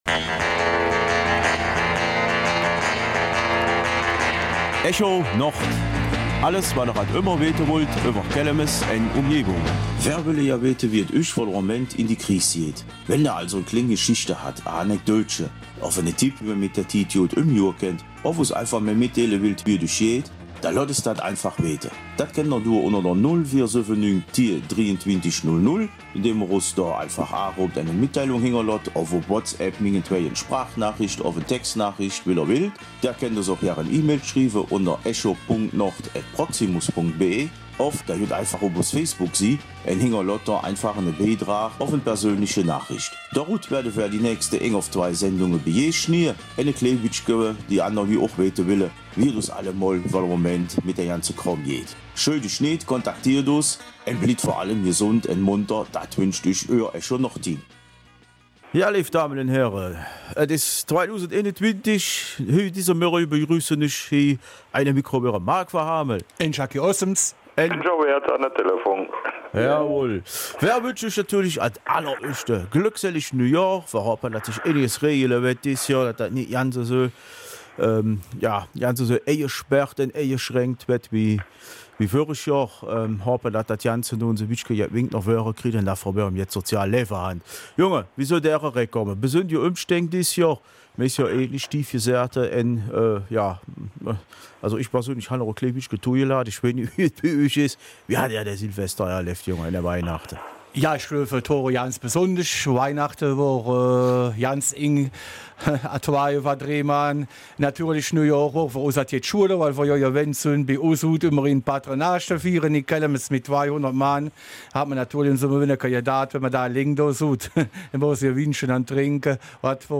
Wie verhält es sich jetzt nach den Feiertagen mit der Corona-Pandemie? Dieser Frage geht das Moderatorenteam der Kelmiser Mundartsendung nach.
Kelmiser Mundart